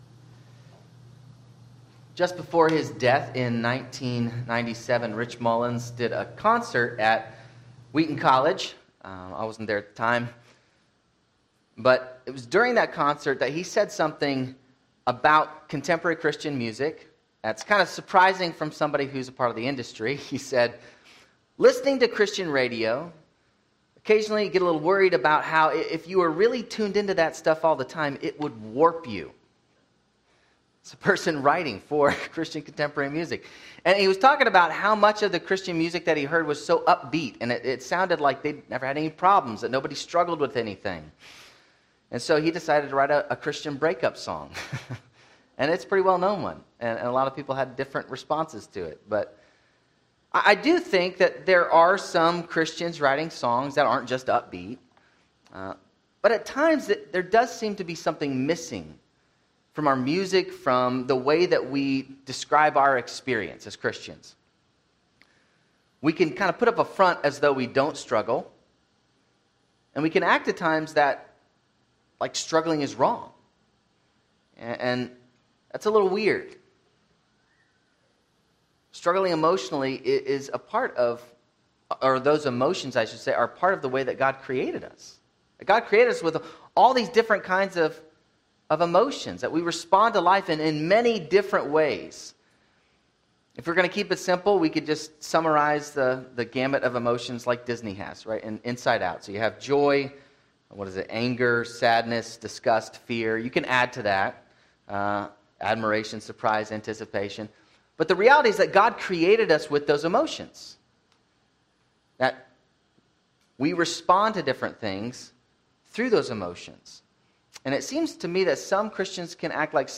Sermons
Service Type: Sunday 10:30am